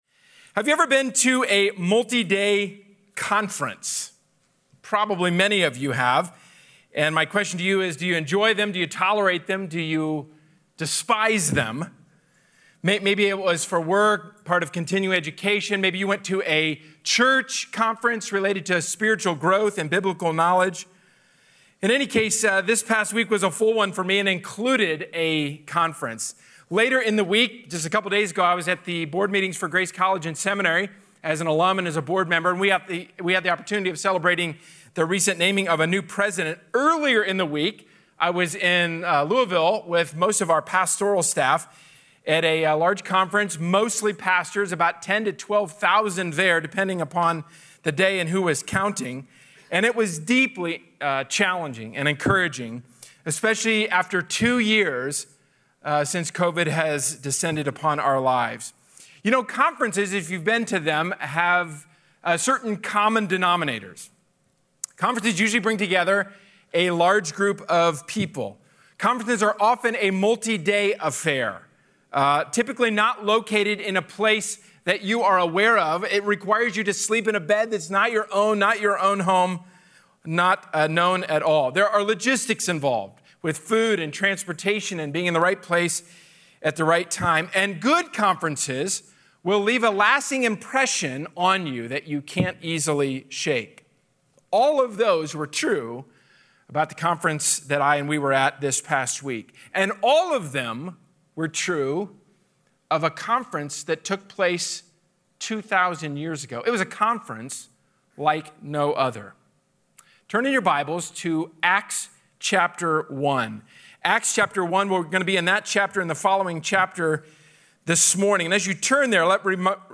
A sermon from the series "Can I Get a Witness."